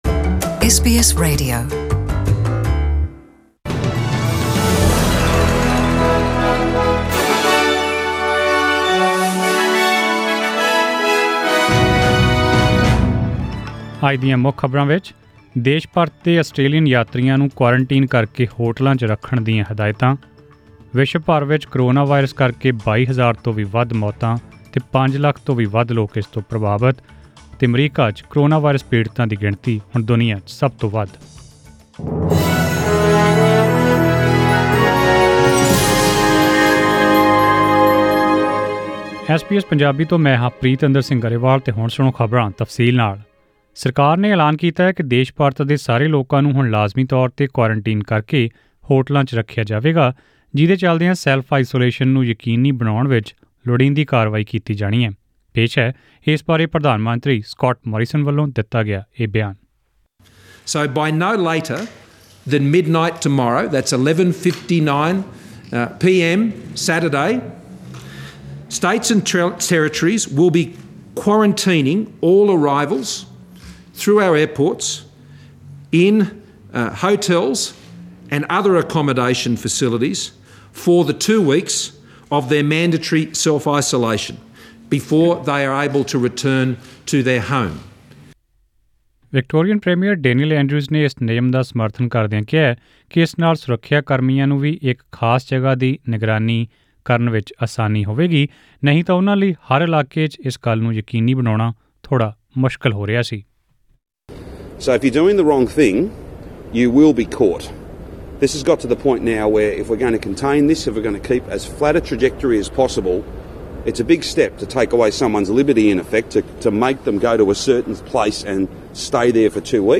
Australian News in Punjabi: 27 March 2020